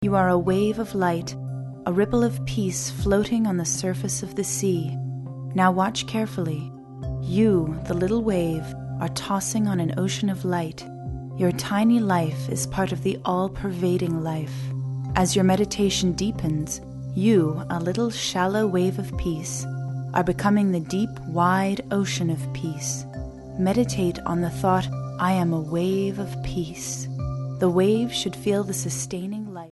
Sprecherin englisch.
Sprechprobe: eLearning (Muttersprache):